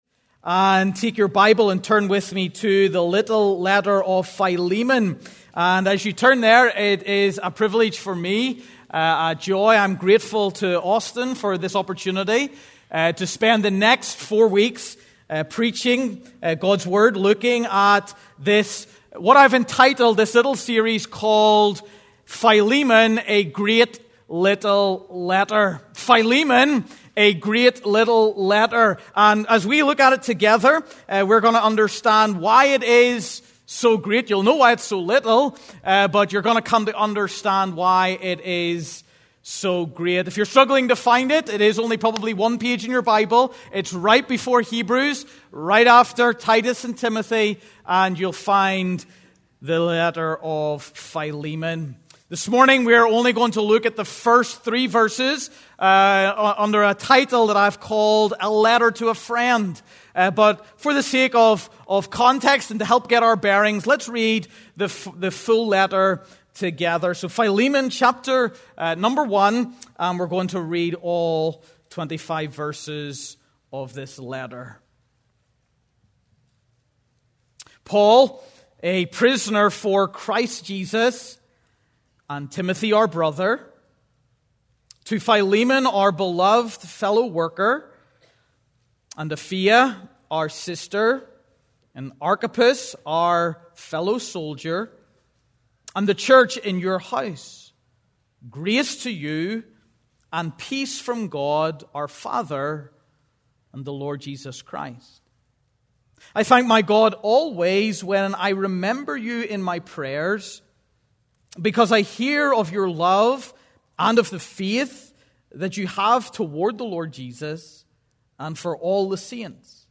Due to technical difficulties this sermon is incomplete.